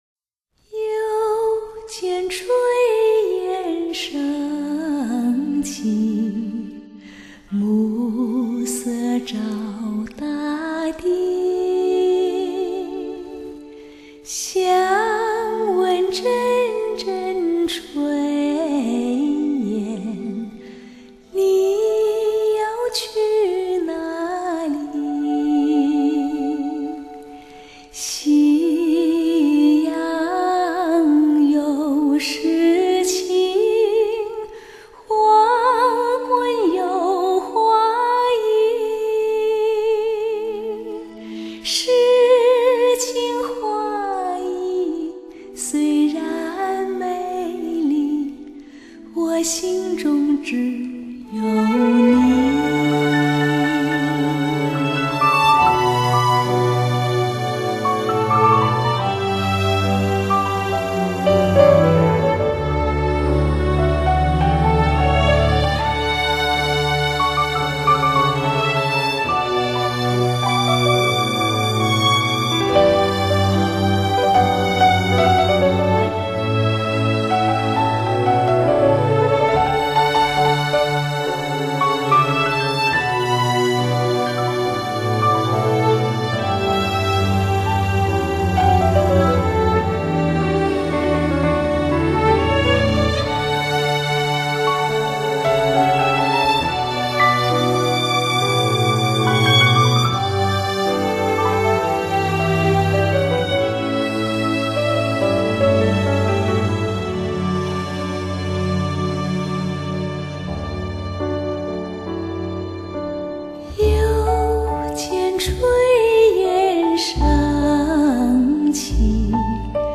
清唱为主，融入美丽的弦乐与钢琴，人声一流